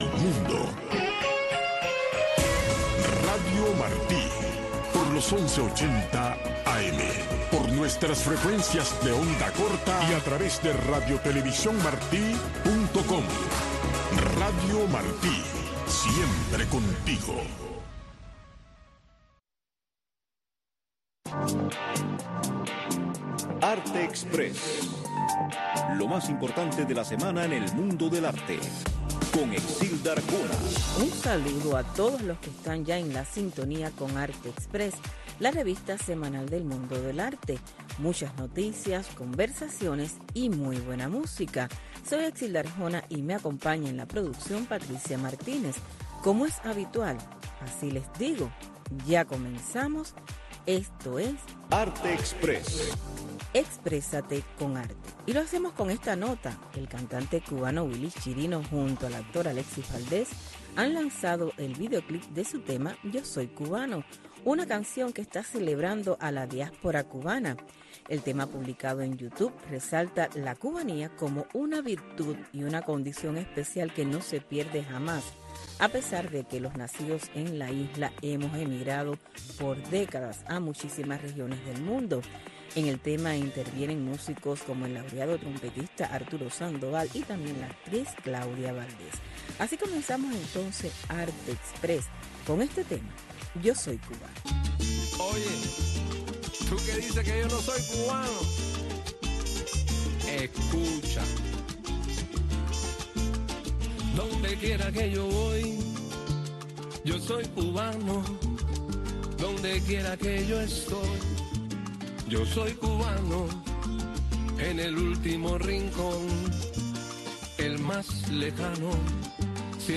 Arte Express, una revista informativa - cultural con noticias, eventos, blogs cubanos, segmentos varios, efemérides, música y un resumen de lo más importante de la semana en el mundo del arte.